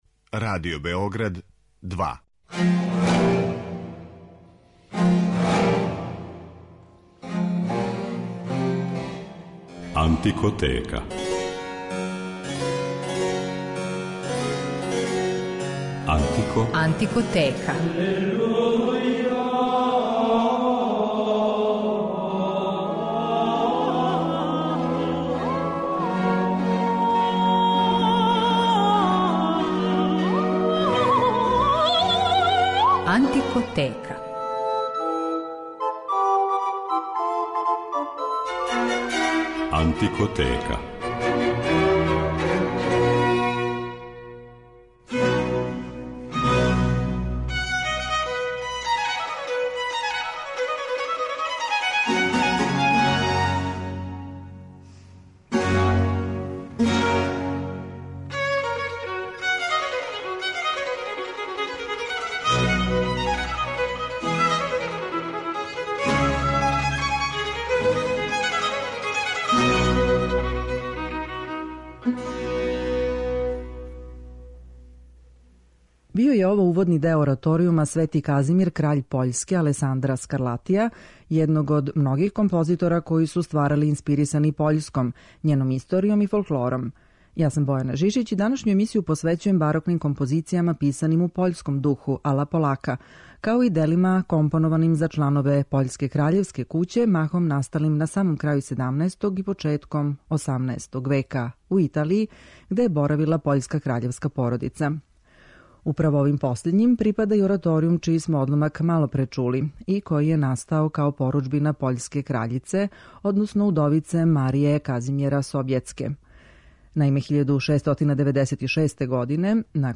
Барокним композицијама писаним у пољском духу, као и делима компонованим за чланове пољске краљевске куће, махом насталим на самом крају 17. и почетком 18. века у Италији, где је боравила пољска краљевска породица, посвећена је данашња емисија.